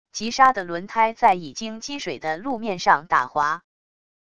急刹的轮胎在已经积水的路面上打滑wav音频